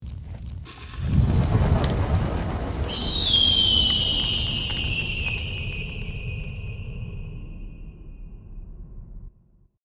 Animals
Phoenix Rebirth is a free animals sound effect available for download in MP3 format.